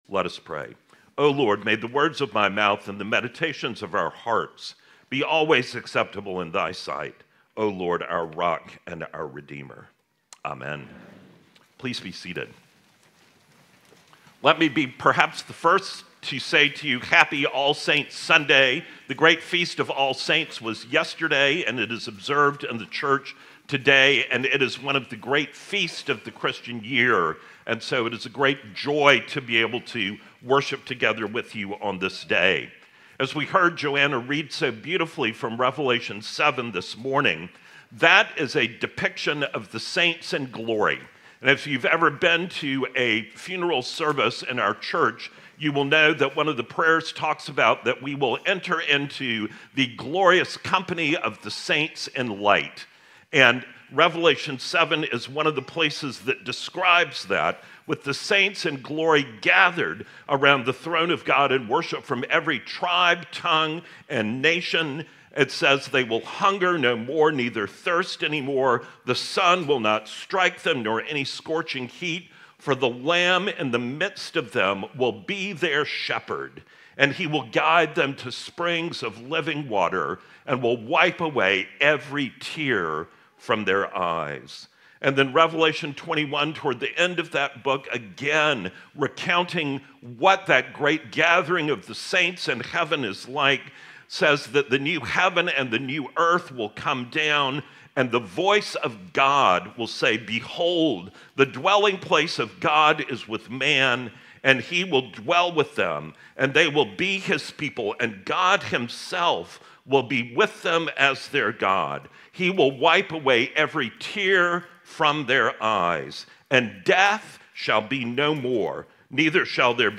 November 2, 2025 ~ All Saints' Sunday: Holy Eucharist and Baptism